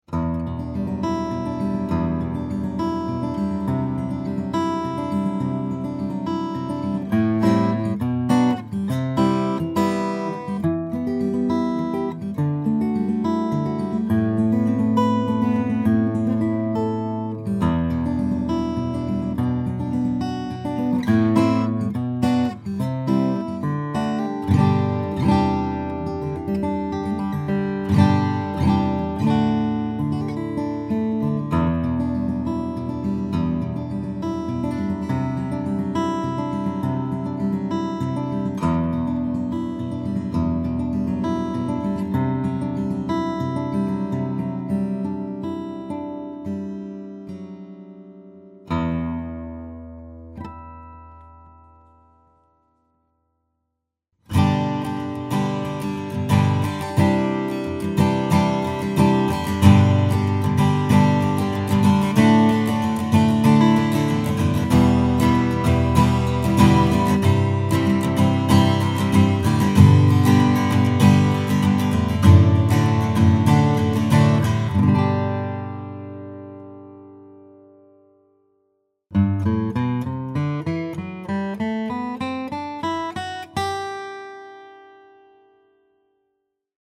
The voice is big, open and mature. The Engelmann Spruce top pairs nicely with Koa to create a versatile voice suitable for many style.